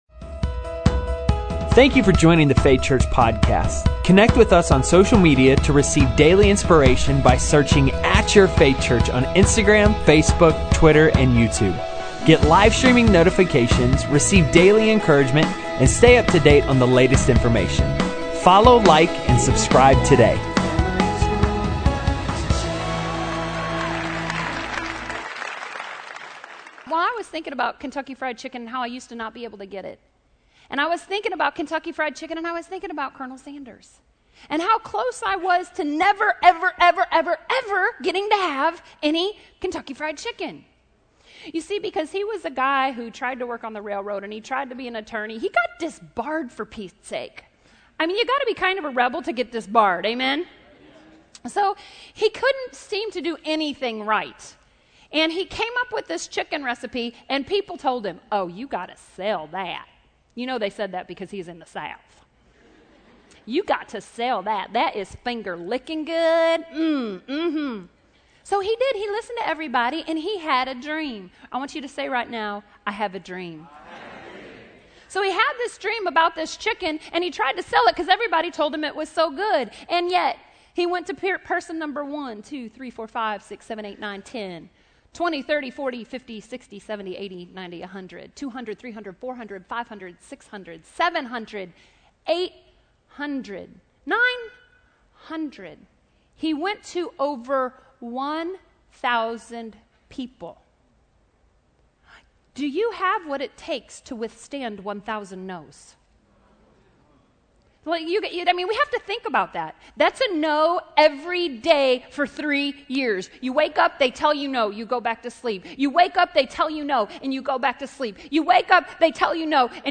Faith Church Audio Podcast